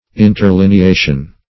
Interlineation \In`ter*lin`e*a"tion\, n. [Cf. F.
interlineation.mp3